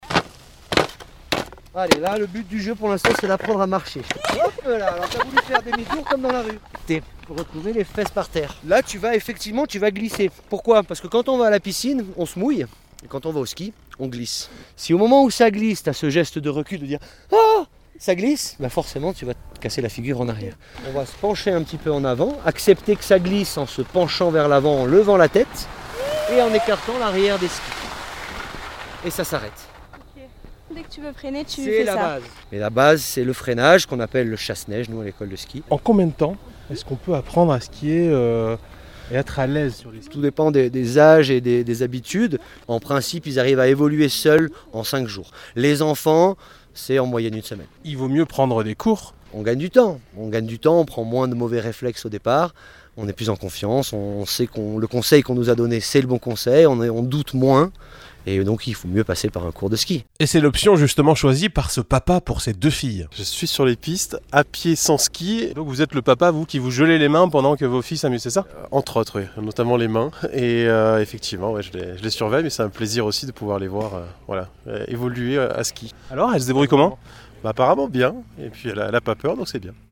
En plein cours sur une piste verte, il donne ses précieux conseils.